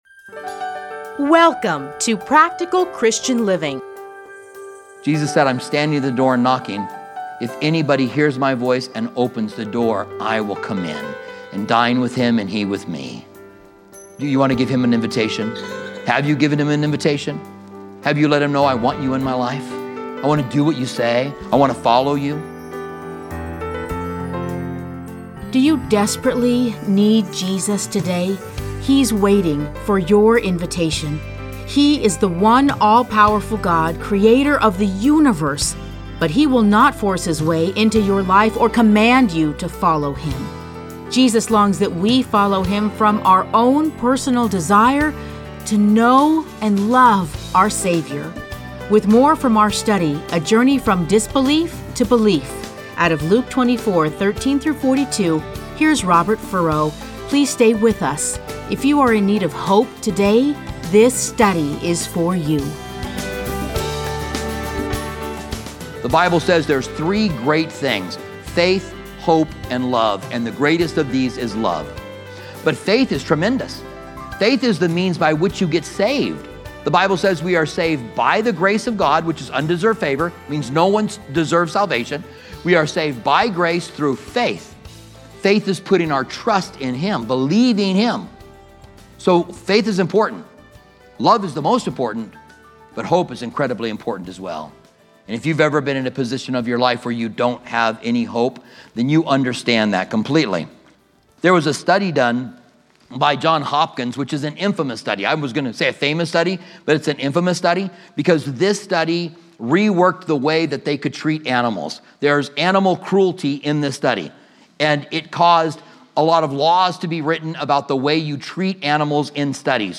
Listen to a teaching from Luke 24: 13-42.